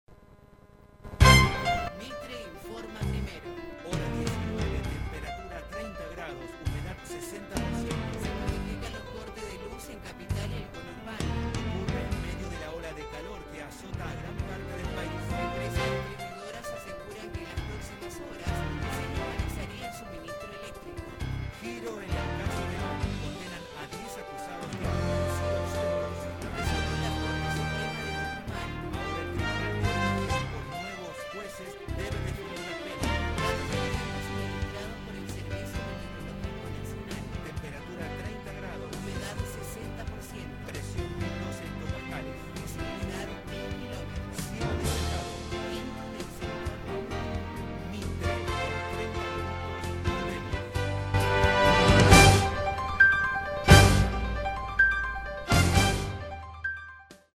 Masculino
NOTICIERO A 2 VOCES